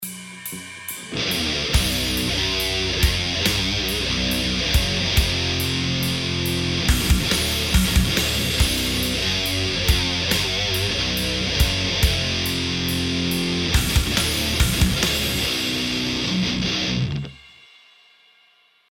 Вложения schecter_and_amp2_test_29.mp3 schecter_and_amp2_test_29.mp3 443,4 KB · Просмотры: 204